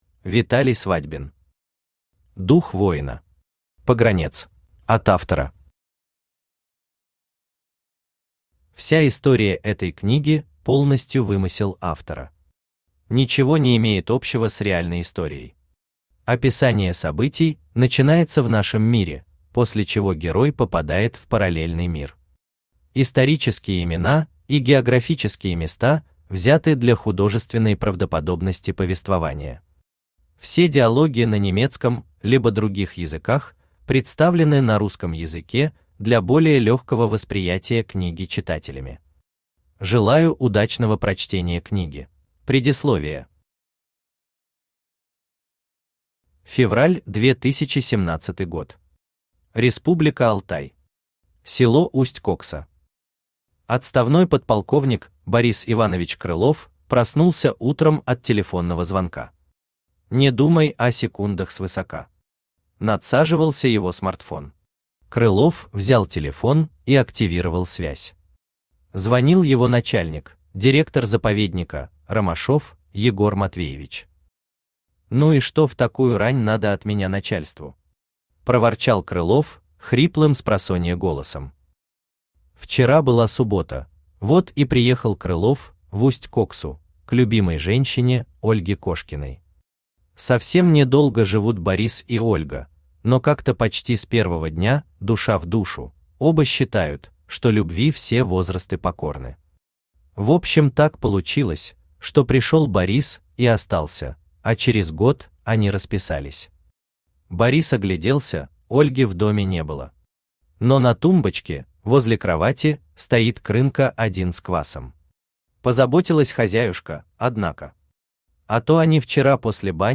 Аудиокнига Дух воина. Погранец | Библиотека аудиокниг
Погранец Автор Виталий Свадьбин Читает аудиокнигу Авточтец ЛитРес.